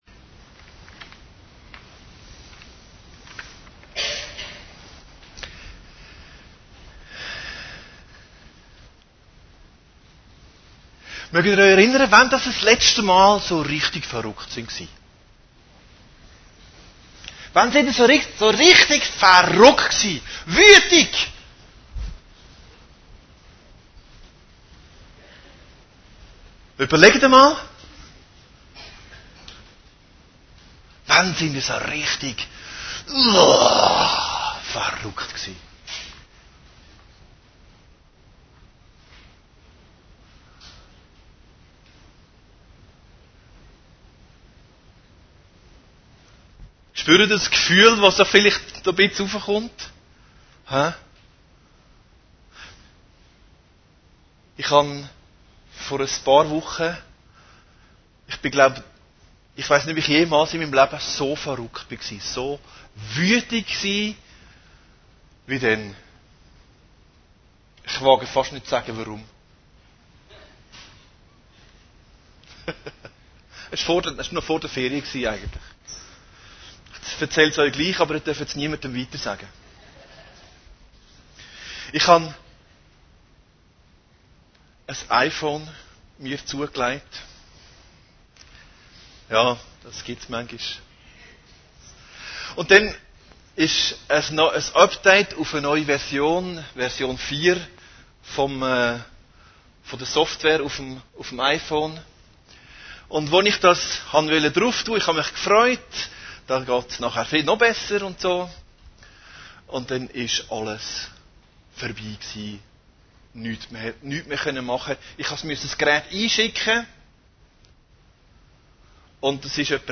Predigten Heilsarmee Aargau Süd – Jesus reinigt den Tempel